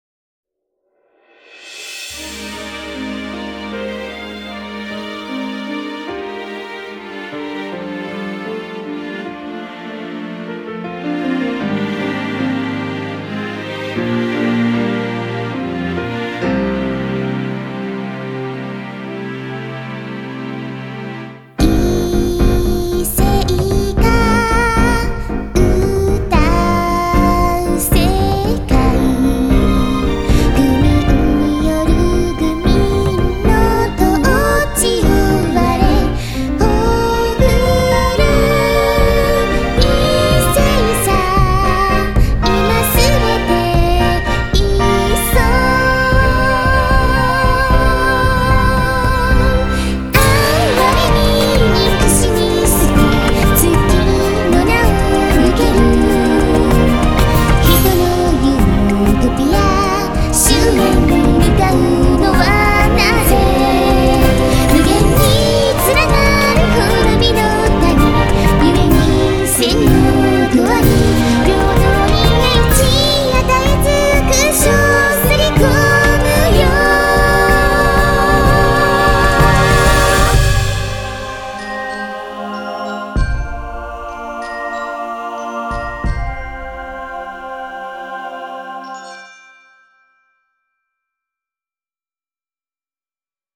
ジャンルイメージソング＋ボイスドラマ